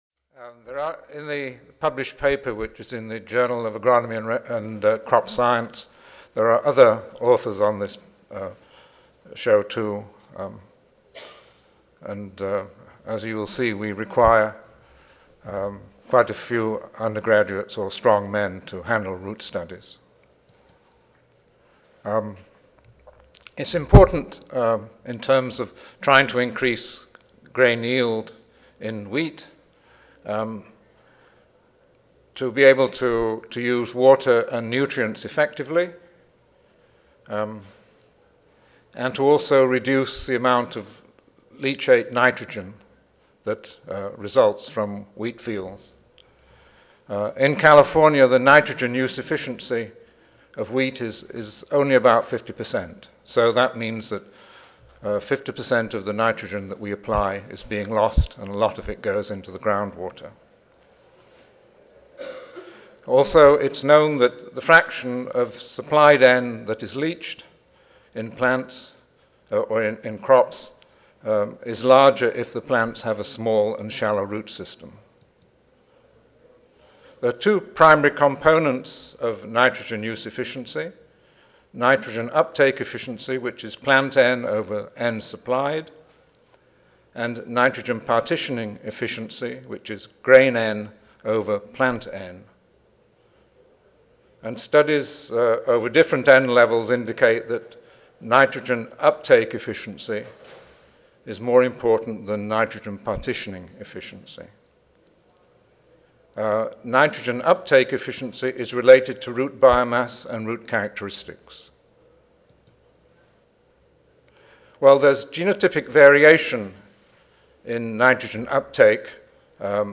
Riverside Audio File Recorded presentation